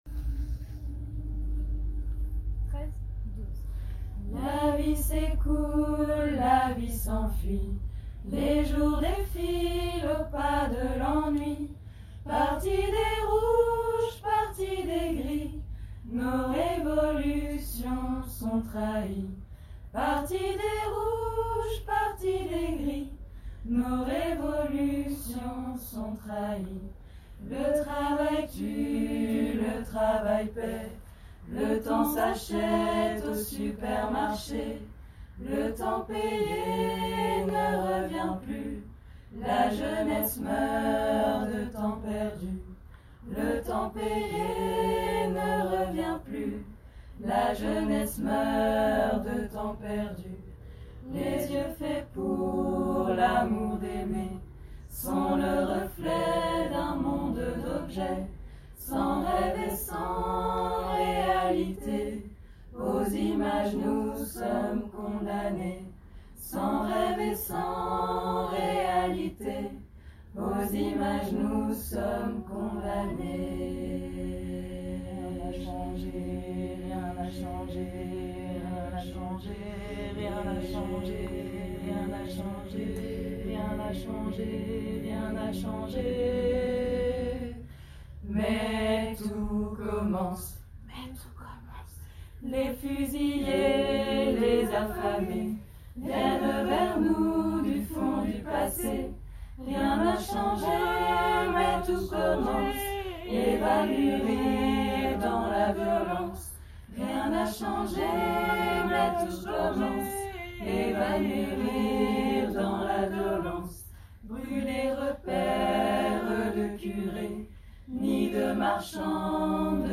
4. lead + basse + haute + contrechant
Note de départ : ré